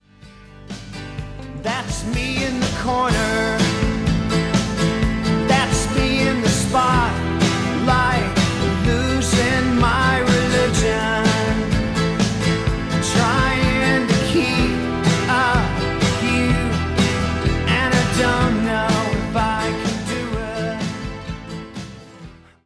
Tónica La